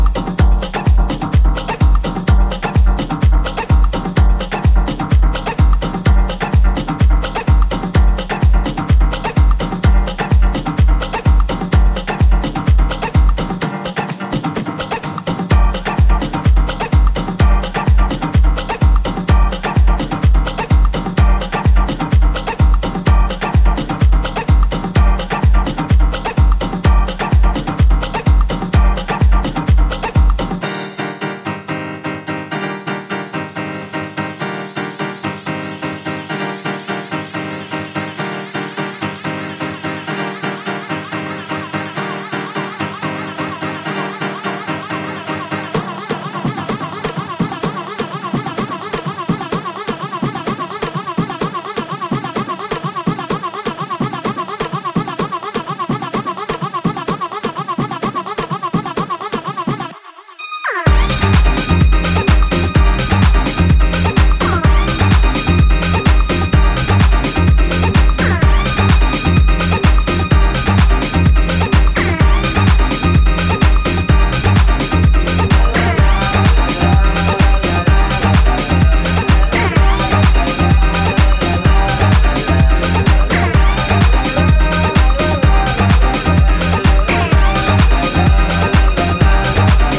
ピアノリフがオーセンティックなイタリアン・ハウスを思わせる